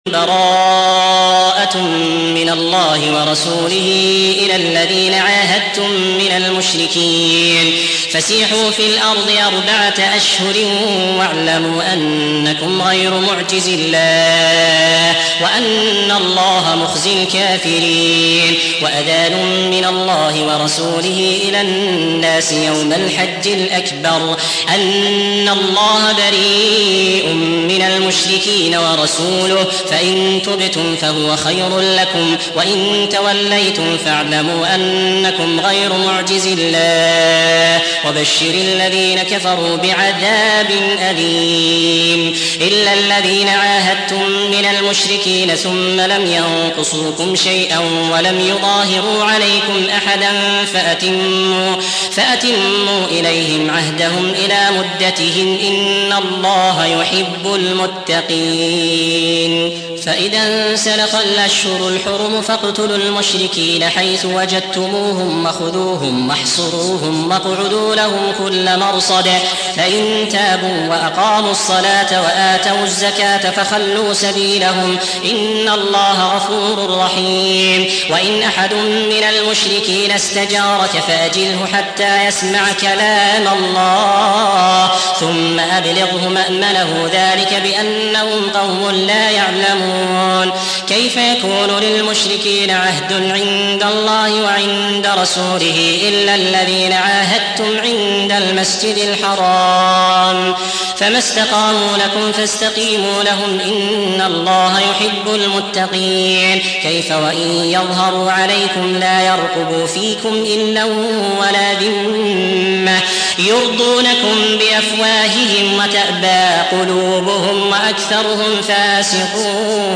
9. سورة التوبة / القارئ